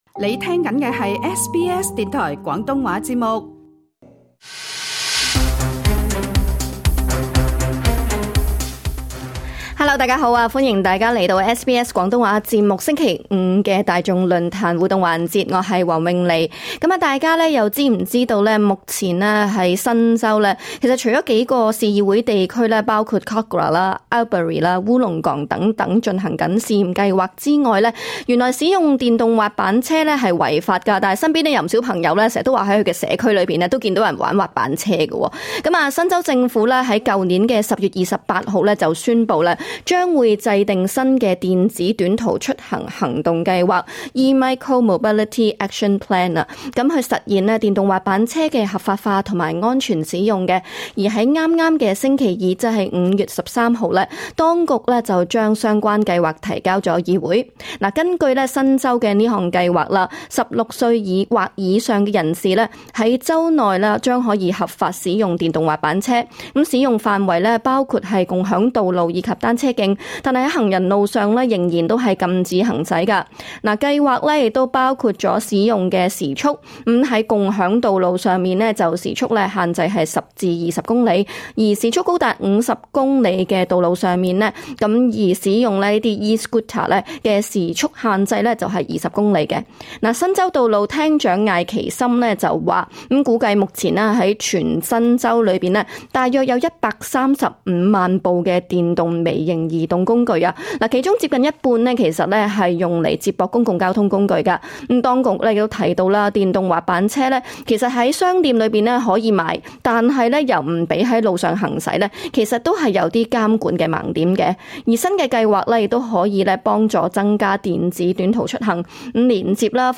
亦有作為汽車駕駛者的聽眾，分享在道路上親身遇見、跟大家息息相關的問題。